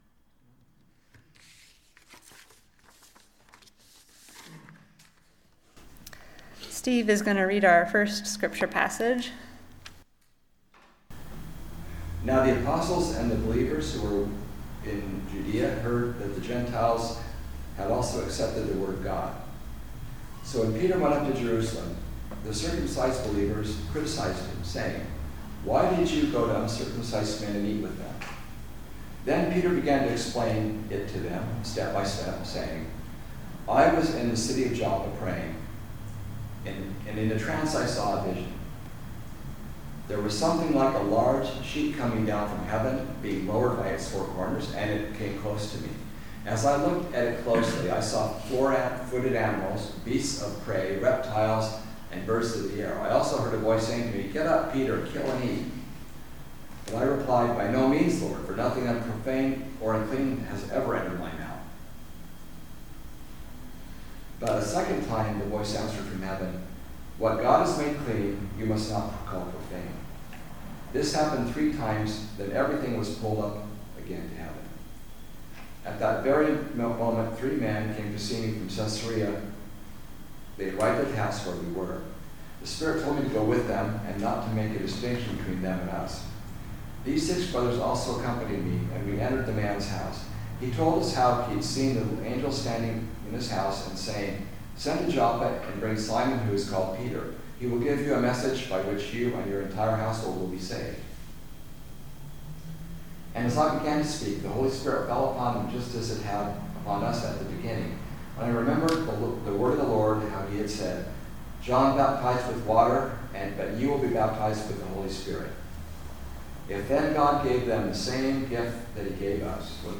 Listen to a recent message, “Love One Another,” from Sunday worship at Berkeley Friends Church.